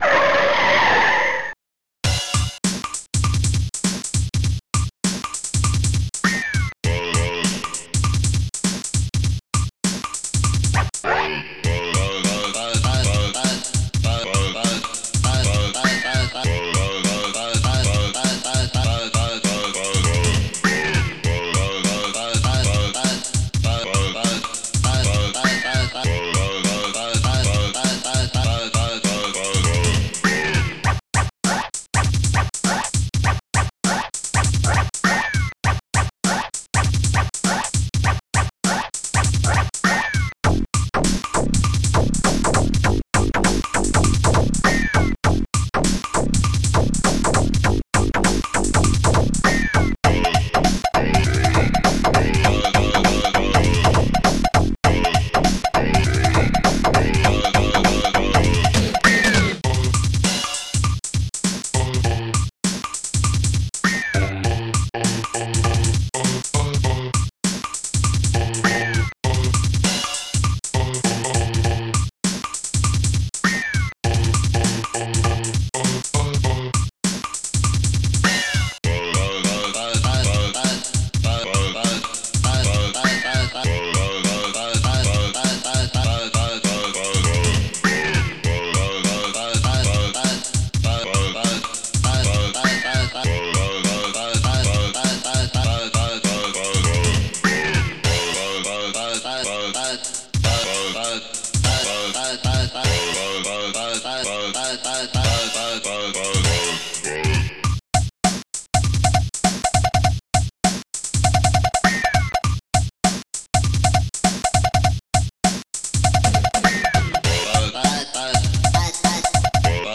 cowbell discobass
cymbal bassdrum hihat snaredrum tom
rimshot scratch1 scratch2